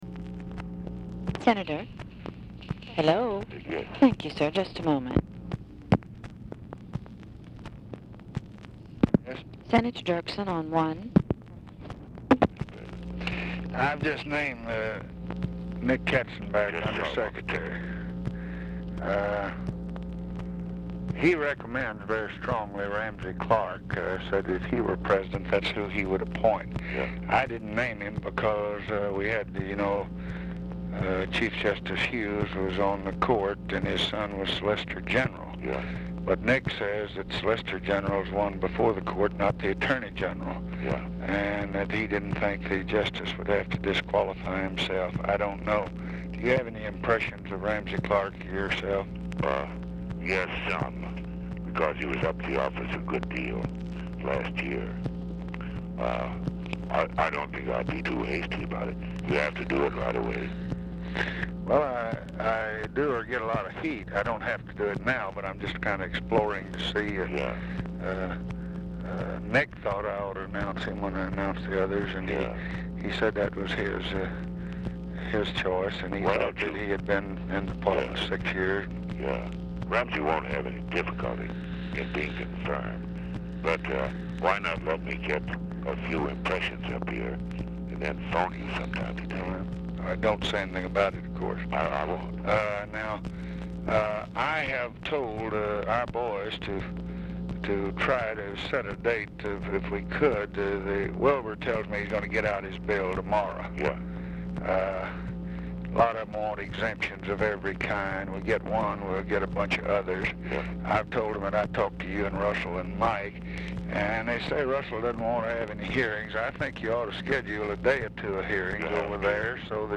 Telephone conversation # 10817, sound recording, LBJ and EVERETT DIRKSEN, 9/21/1966, 11:41AM | Discover LBJ
Format Dictation belt
Location Of Speaker 1 Oval Office or unknown location